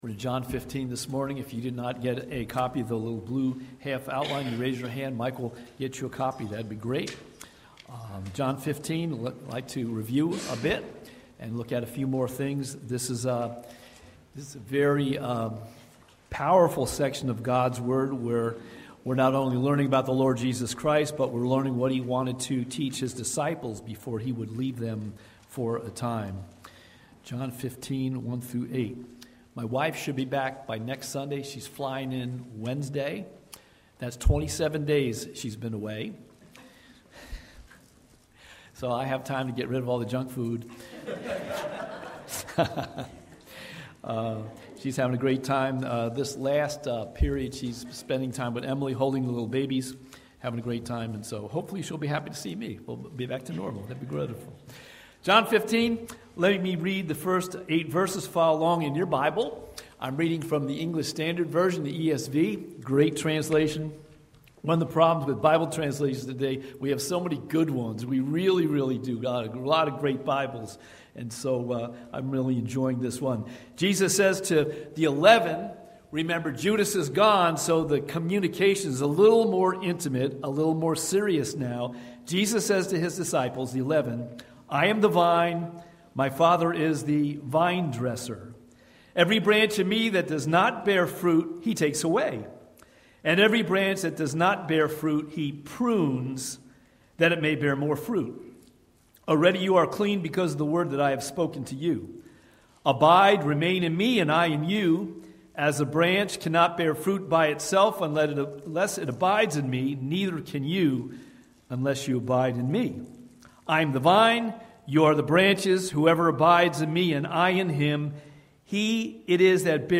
Watch Online Service recorded at 9:45 Sunday morning.